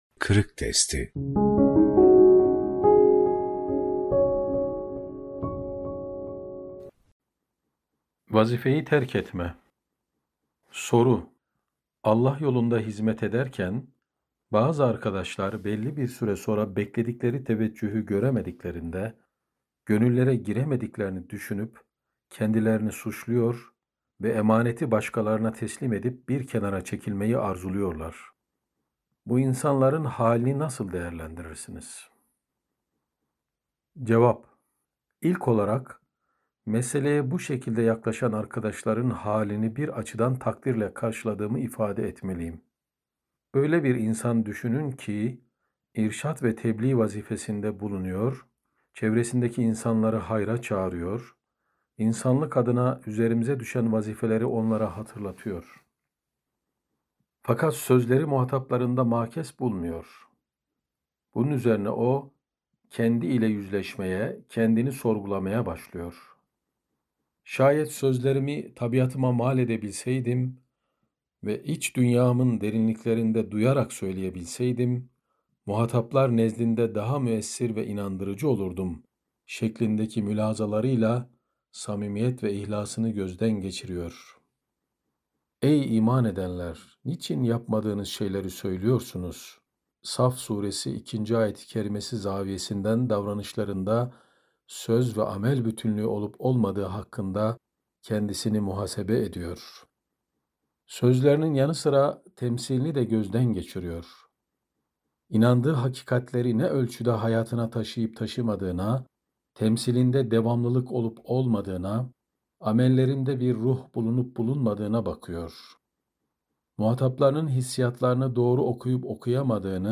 Vazifeyi Terk Etme - Fethullah Gülen Hocaefendi'nin Sohbetleri